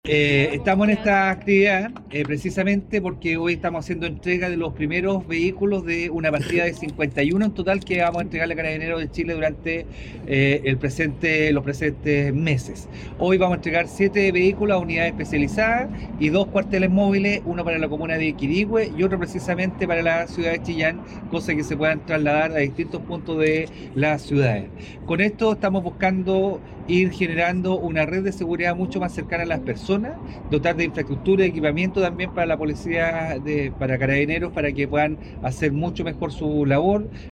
Con esto, se busca ampliar la cobertura y mejorar la capacidad de respuesta de Carabineros ante situaciones delictuales y operativos preventivos, dijo el gobernador regional Óscar Crisóstomo, quien destacó además que la inversión regional en seguridad ya alcanza los 30 mil millones de pesos, incluyendo iniciativas con la PDI.